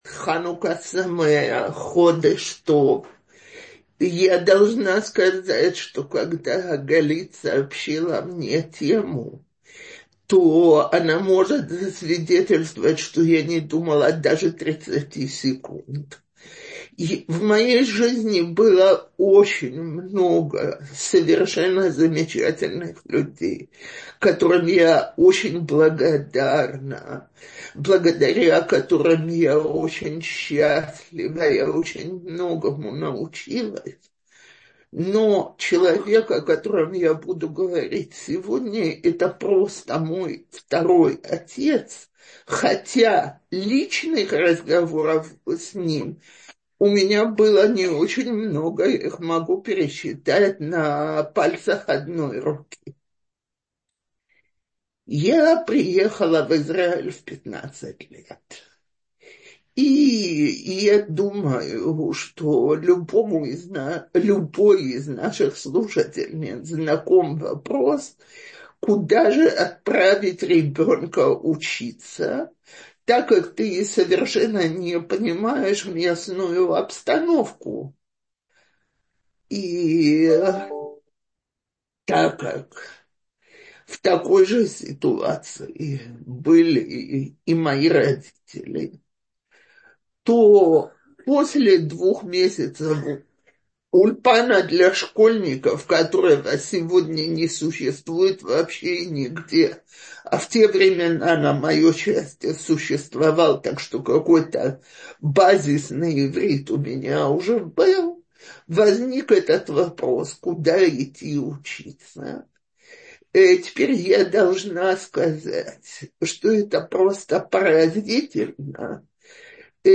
Утреннее Zoom ток-шоу «Утро с Толдот» приглашает вас на наш традиционный ханукальный Зум-марафон с кратким «спринт»-включением наших лекторов каждый день праздника.